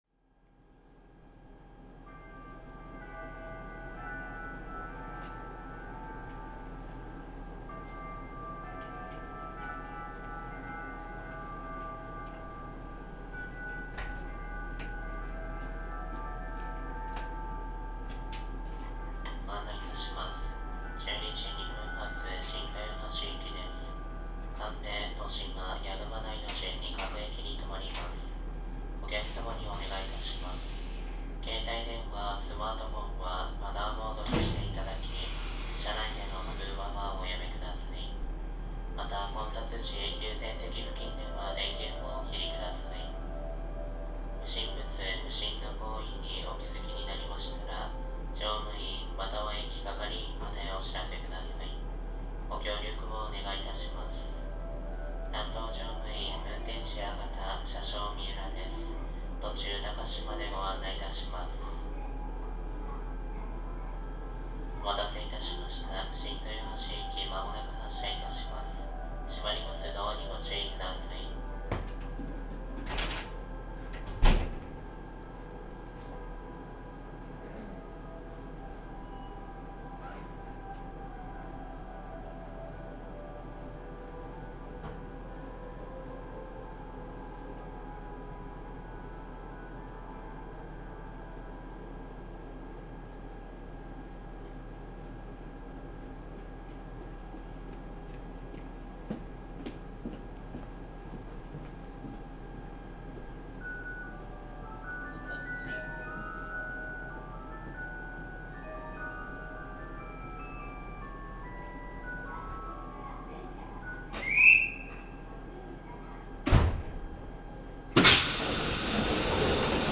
種車の仕様により、日立製と東洋電機製の2種類の主電動機が存在しており、両者で走行音が異なっている。